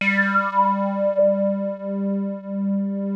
JUP 8 G4 6.wav